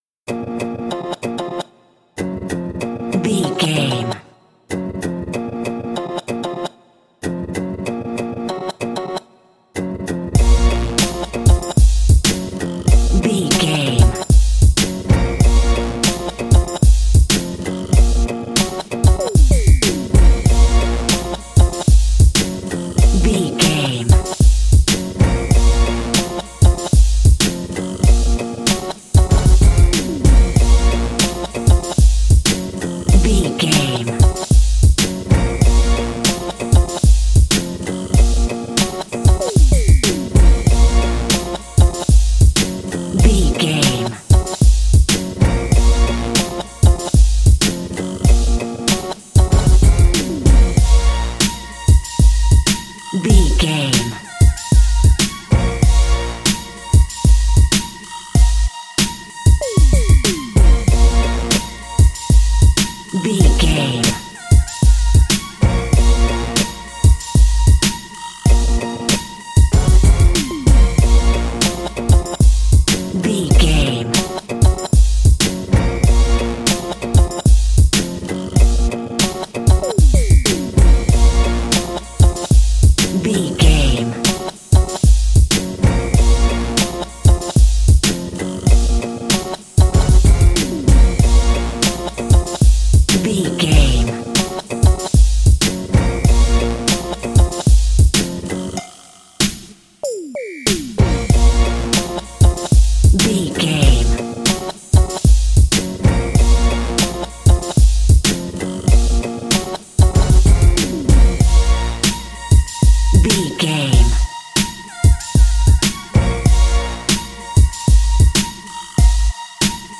Aeolian/Minor
B♭
drum machine
synthesiser
percussion